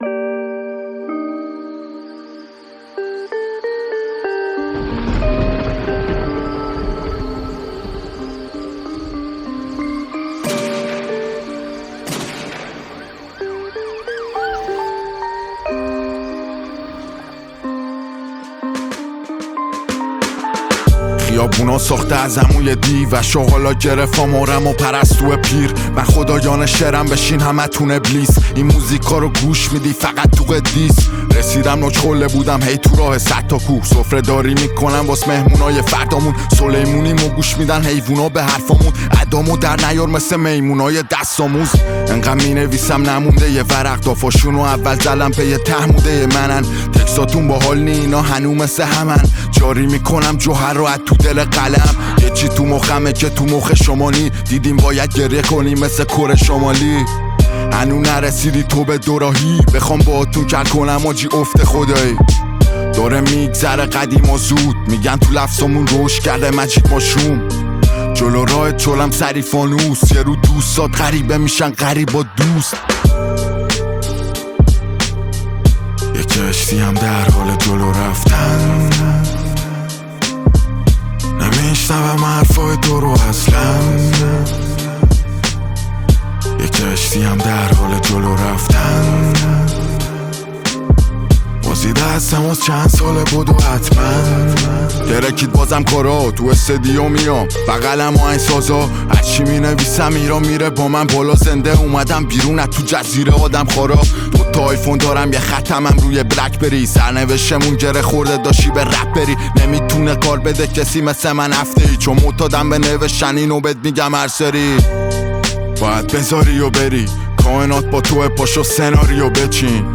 گنگ رپ